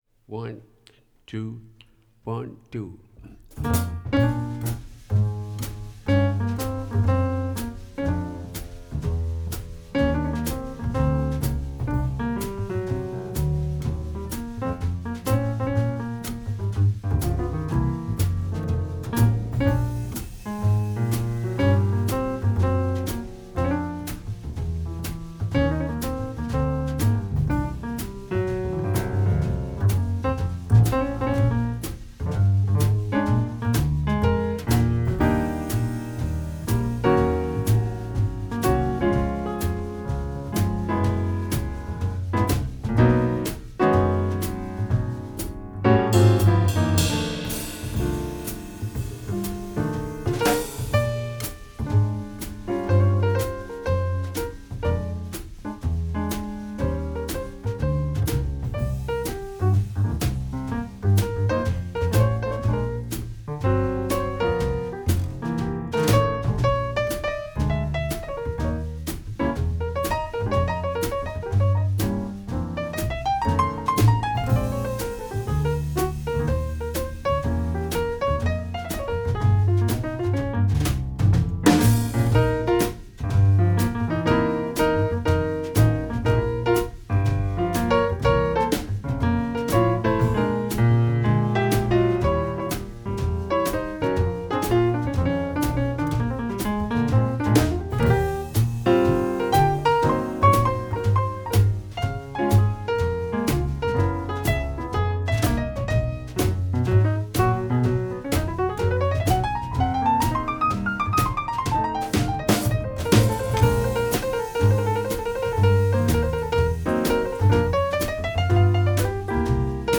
48 kHz mit dCS-Wandler